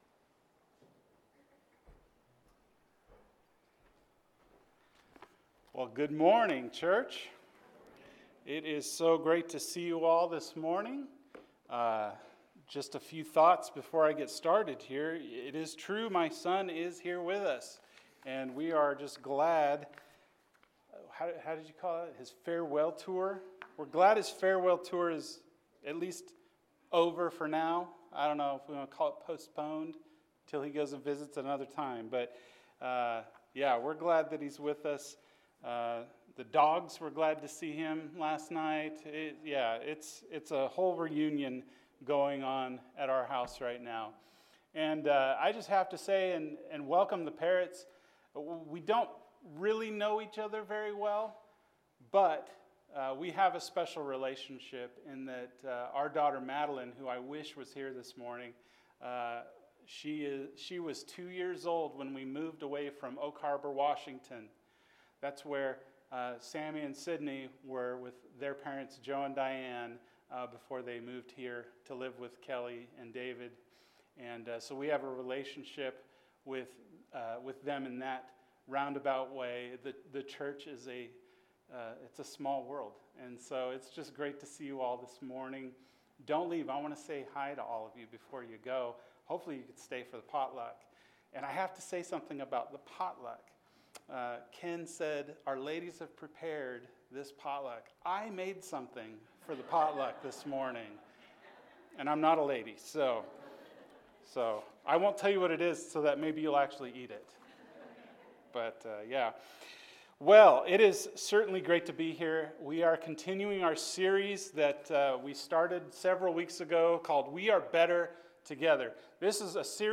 Our Proclamation to the World – 1 Corinthians 11 – Sermon — Midtown Church of Christ